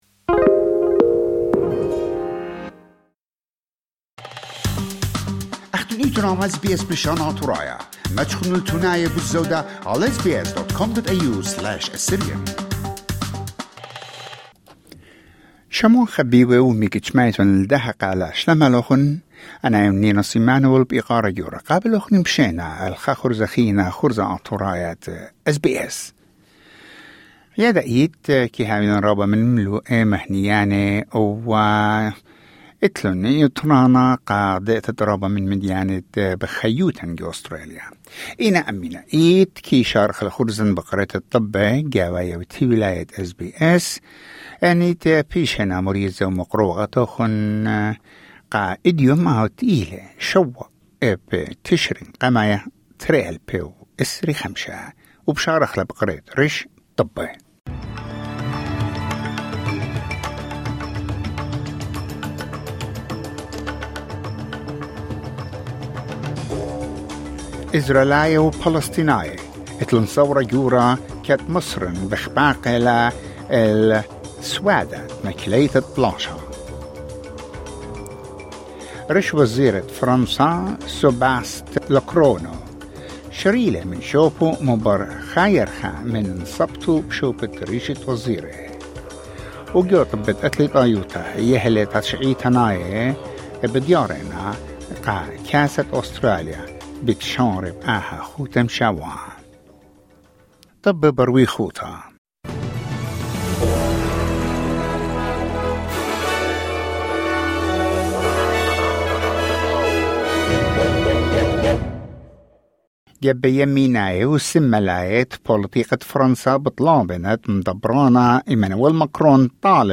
News bulletin: 7 October 2025